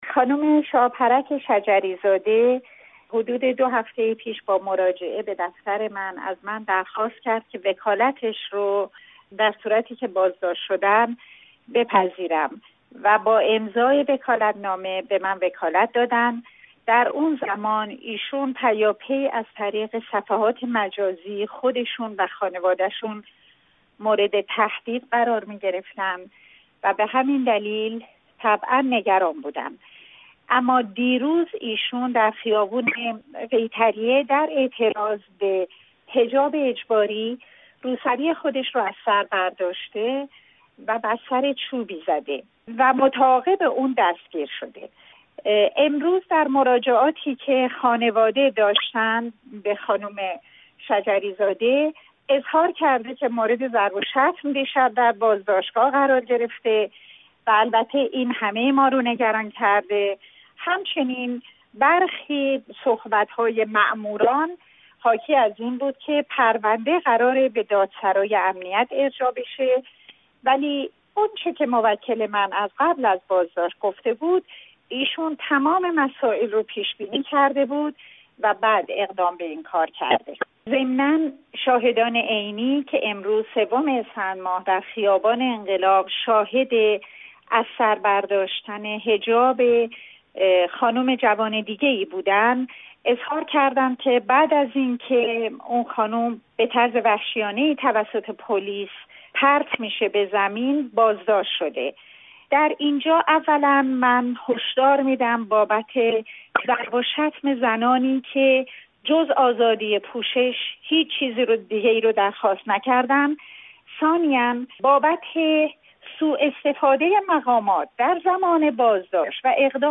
نسرین ستوده از بازداشت معترضان به حجاب اجباری می‌گوید